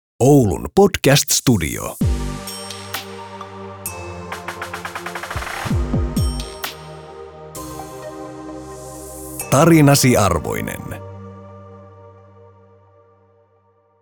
Äänilogo